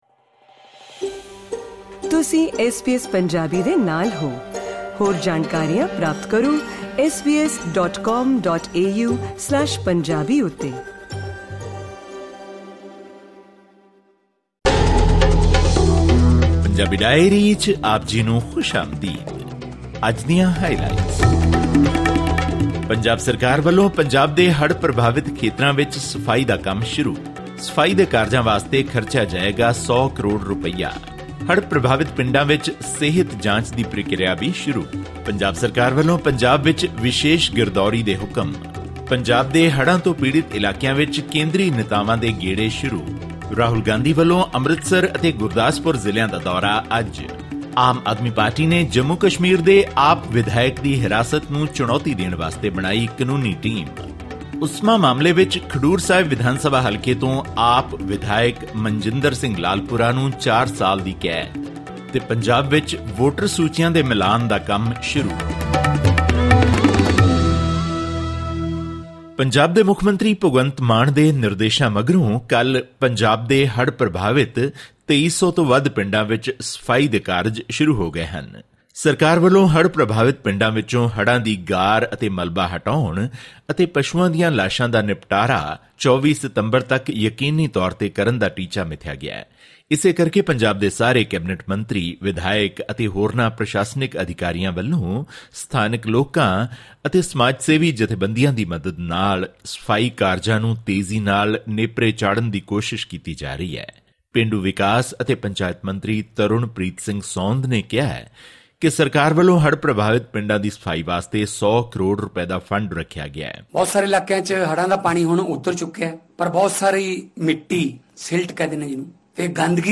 Weekly news from the Indian state of Punjab.